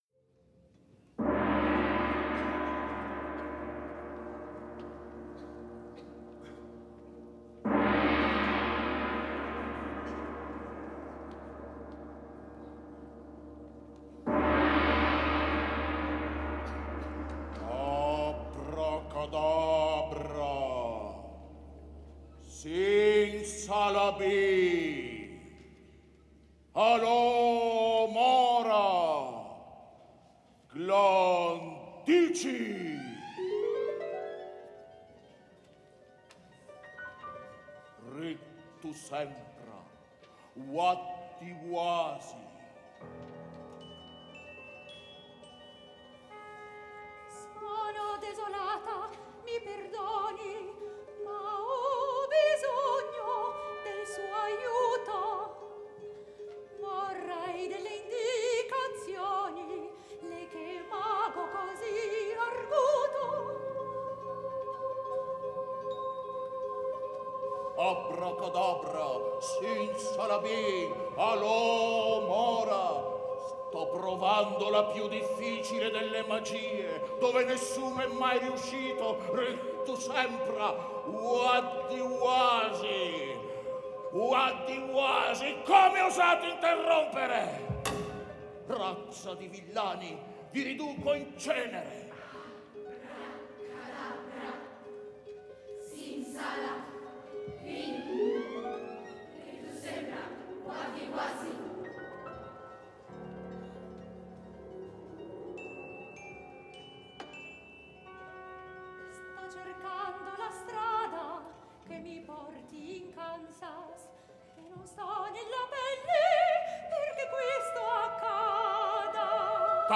Registrazioni della prima rappresentazione italiana del Mago di Oz